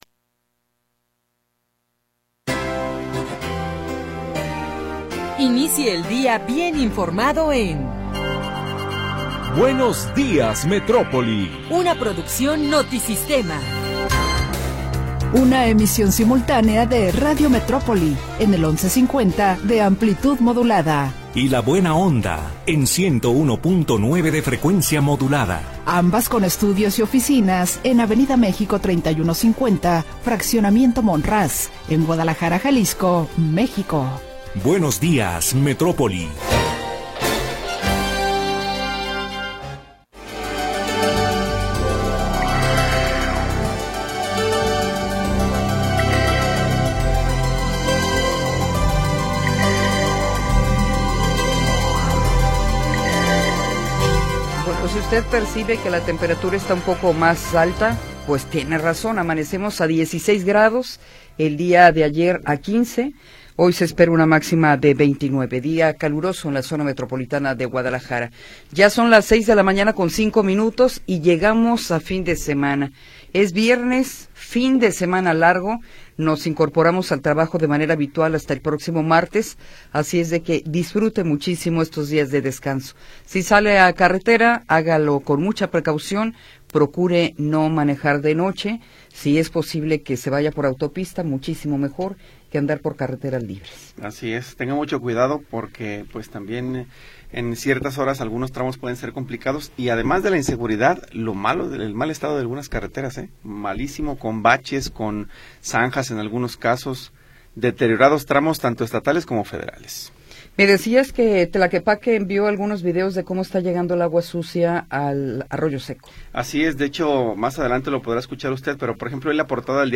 Primera hora del programa transmitido el 13 de Marzo de 2026.